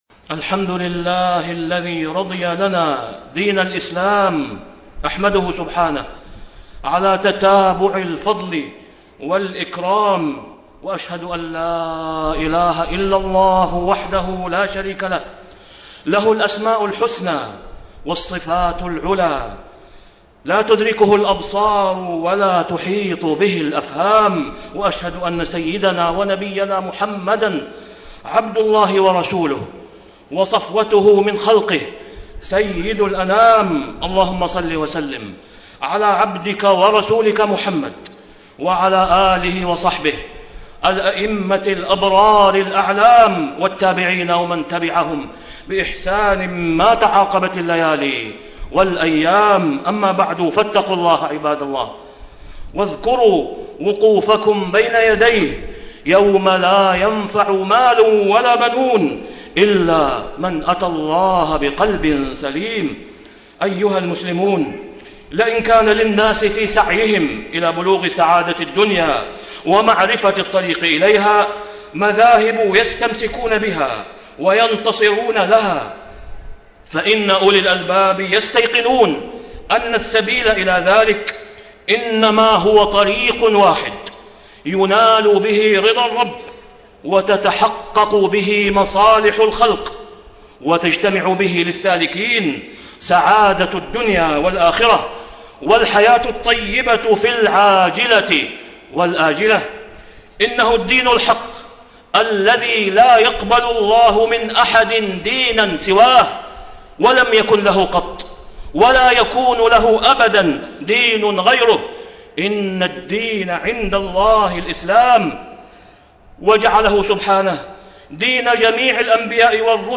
تاريخ النشر ١٤ ربيع الثاني ١٤٣٥ هـ المكان: المسجد الحرام الشيخ: فضيلة الشيخ د. أسامة بن عبدالله خياط فضيلة الشيخ د. أسامة بن عبدالله خياط فضائل ومحاسن دين الإسلام